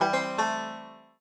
banjo_egc1a.ogg